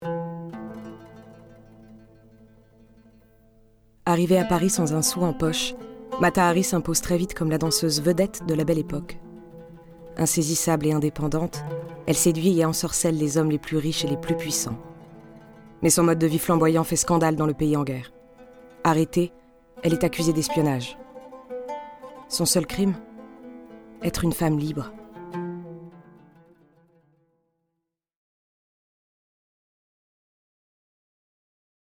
voix off
Comédienne